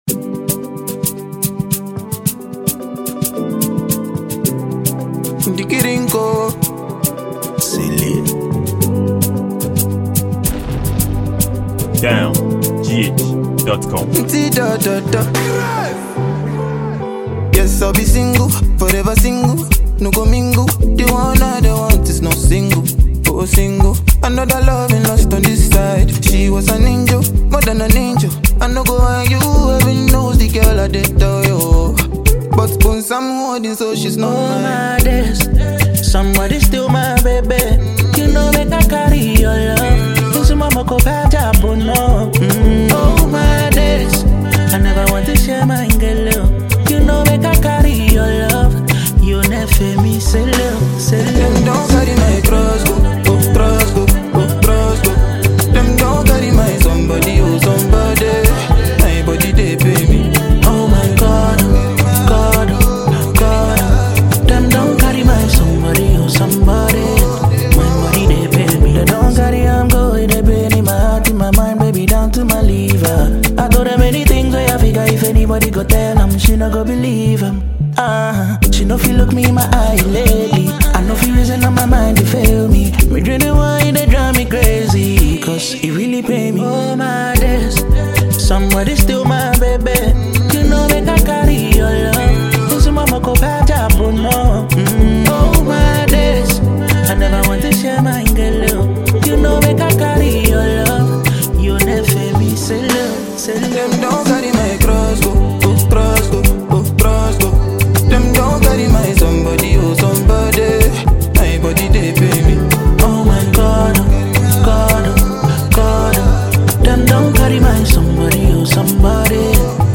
afrobeat highlife music